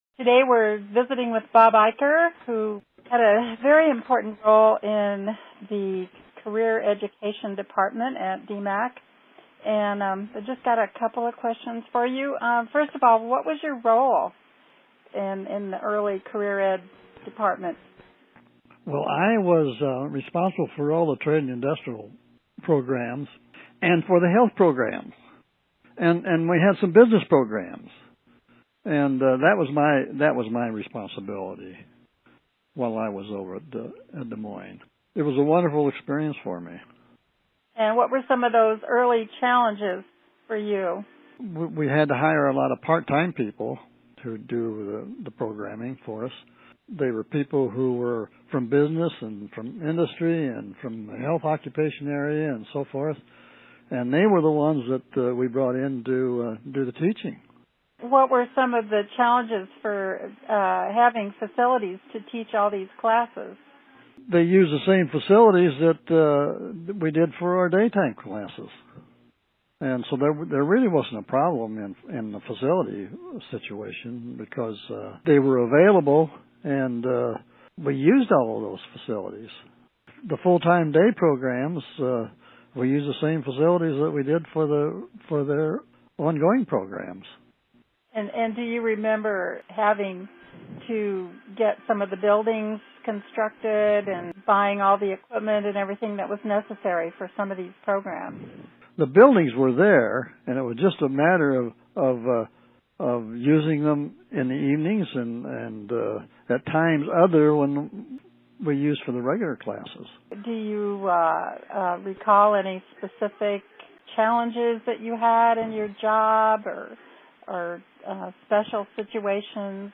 Interviews
Listen to conversations with former DMACC employees by clicking an audio player below.